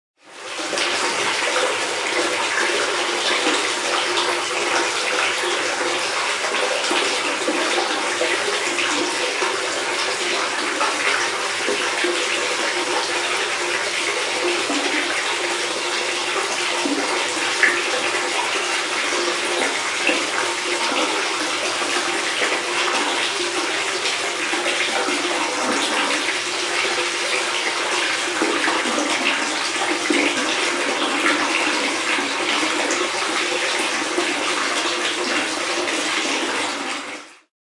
浴缸水打开运行和排放
描述：打开水龙头，用水填充浴缸，然后刻字排水。
标签： 填充 浴缸 填充 沐浴 排水 水龙头 漏极 浴室
声道立体声